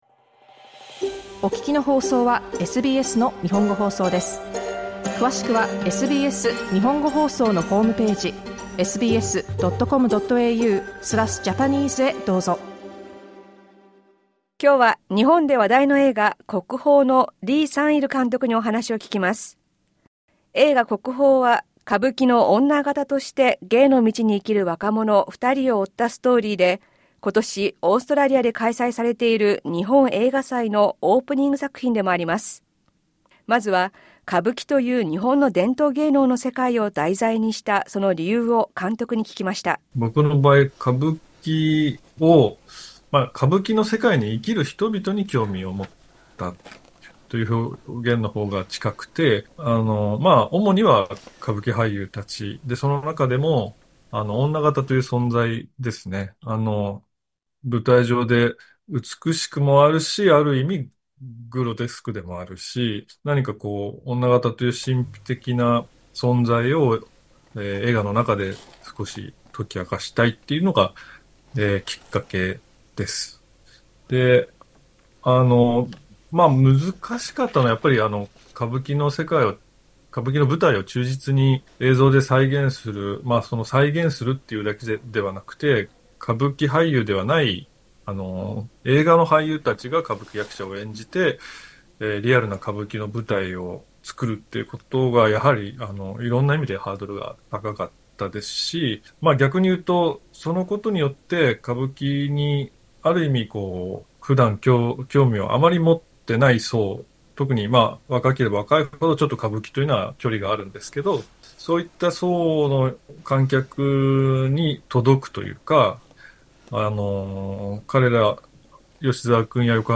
「国宝」李相日監督インタビュー、オーストラリア日本映画祭2025
作品の舞台は伝統芸能・歌舞伎の世界。李相日（リ・サンイル、Lee Sang-il）監督にお話を聞きました。